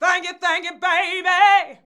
THANK   YA.wav